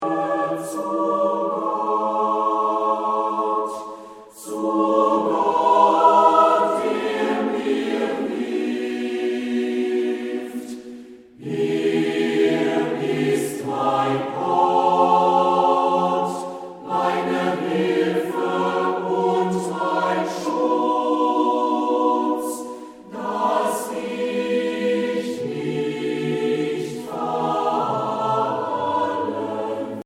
Trostvoll, harmonisch und warm